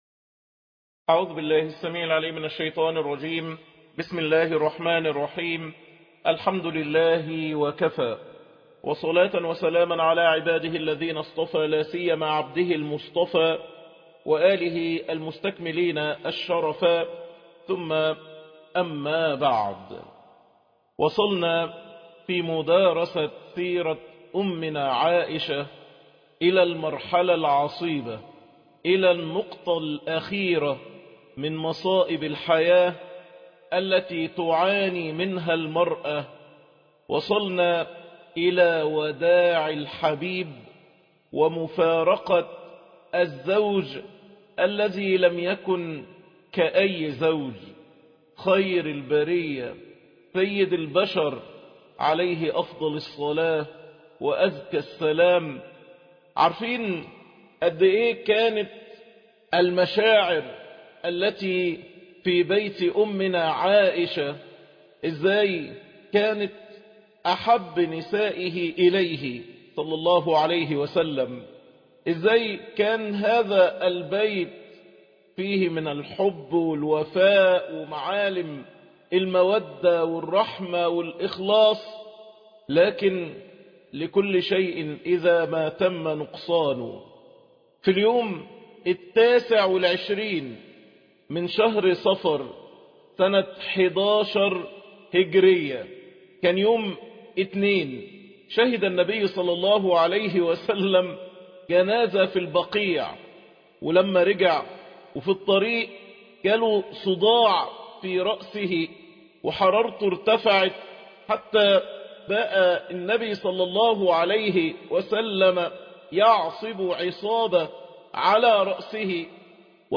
الدرس 21 وداع الحبيب - هؤلاء أمهاتنا